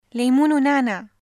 [leemuun w-naʕnaʕ]